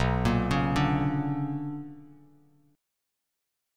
BmM9 chord